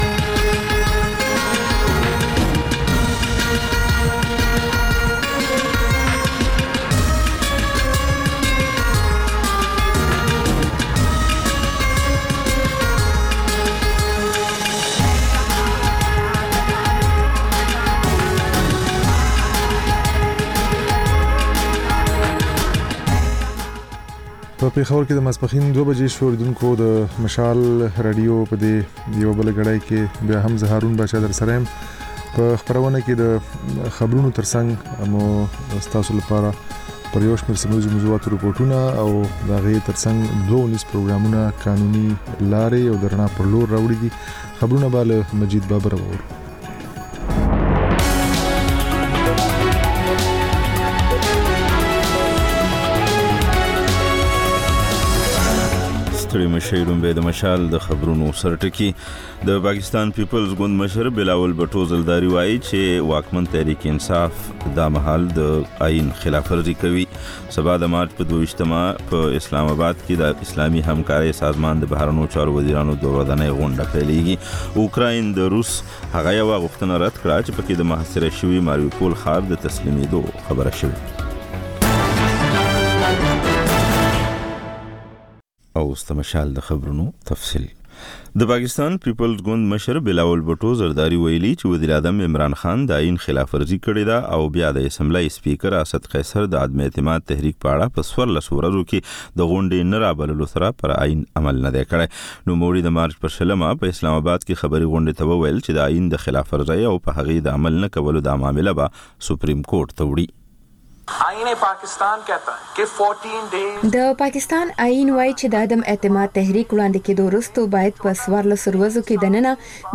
د مشال راډیو دویمه ماسپښینۍ خپرونه. په دې خپرونه کې تر خبرونو وروسته بېلا بېل رپورټونه، شننې، مرکې خپرېږي.